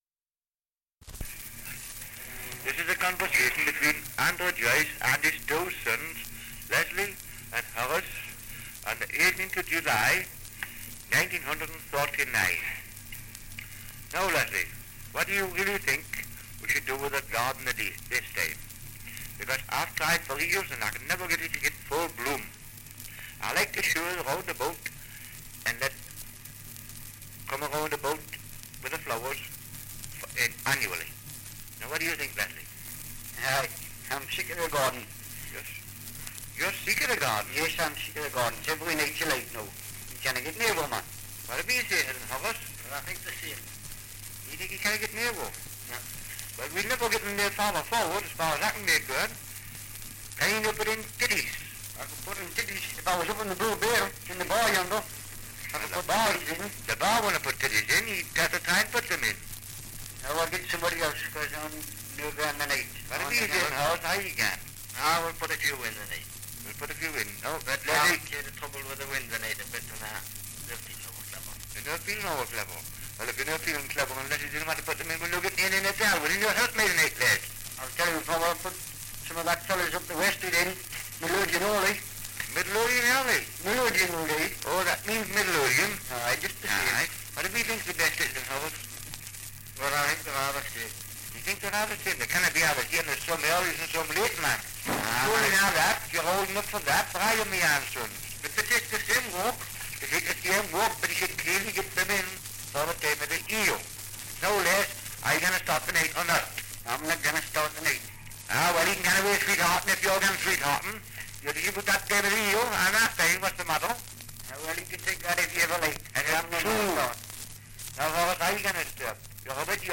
2 - Dialect recording in Belford, Northumberland
78 r.p.m., cellulose nitrate on aluminium